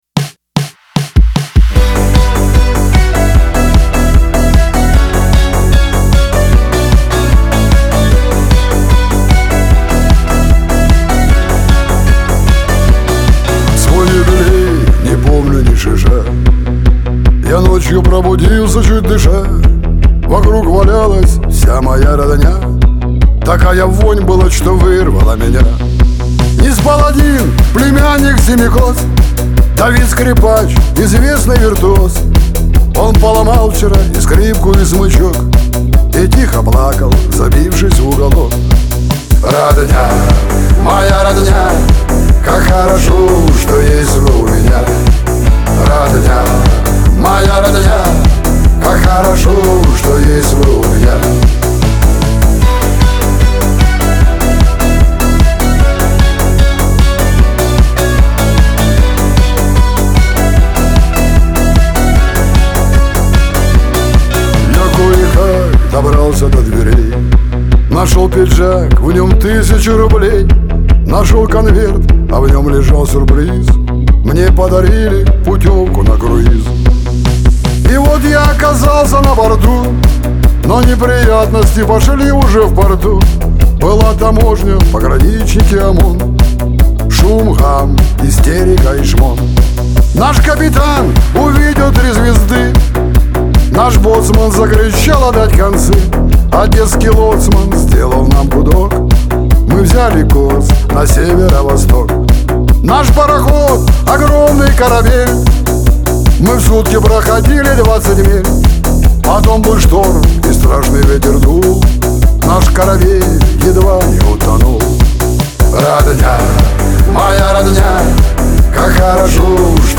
Шансон , грусть
Лирика